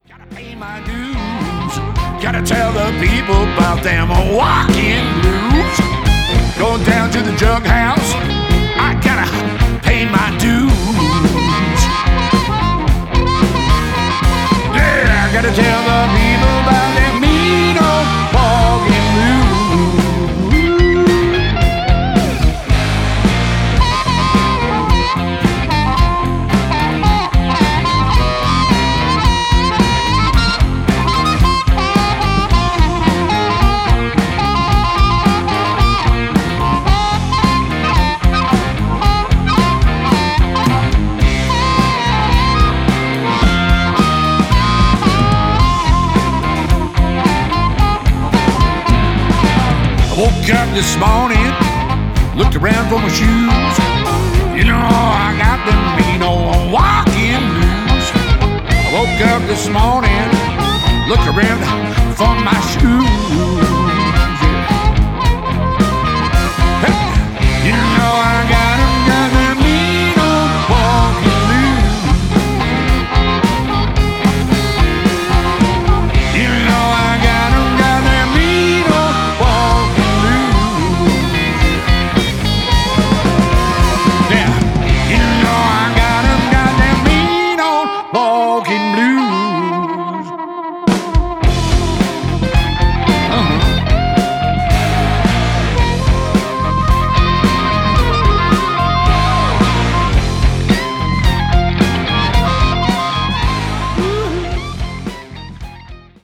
It goes from sparkling clean to brown and creamy distortion.
Walkin' Blues - backing track/solo